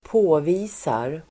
Uttal: [²p'å:vi:sar]